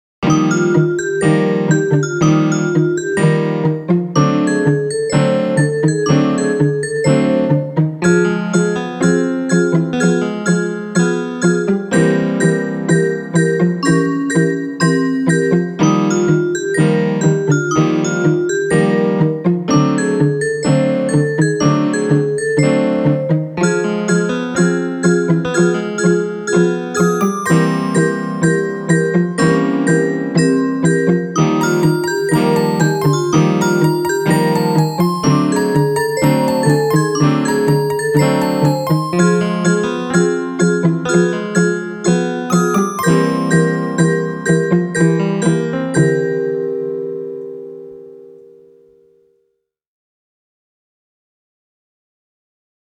Alto Saxophone
Balalaika, Drum Machine